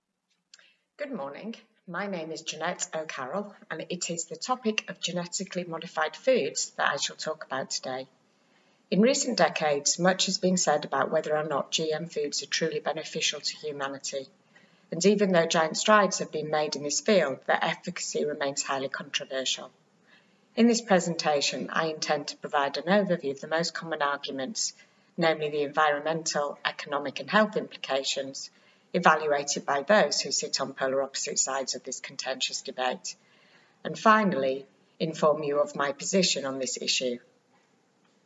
• Exam-ready recorded monologue (MP3)